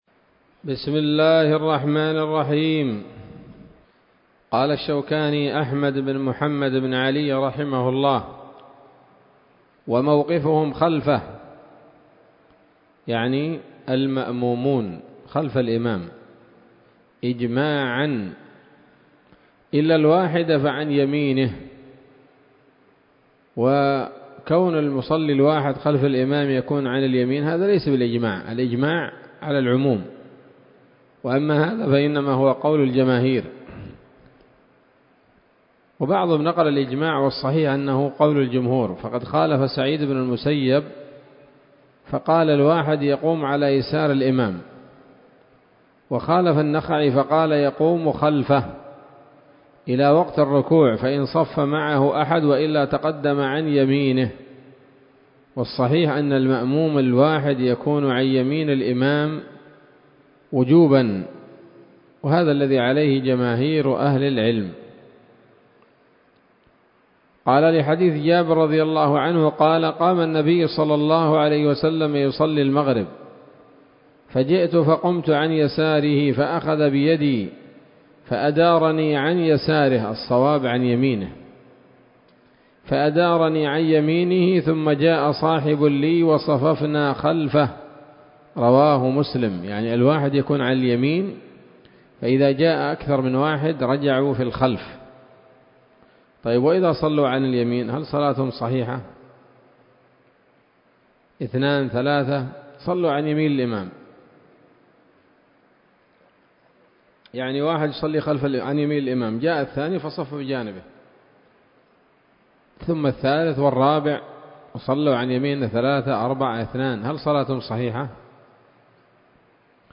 الدرس التاسع والعشرون من كتاب الصلاة من السموط الذهبية الحاوية للدرر البهية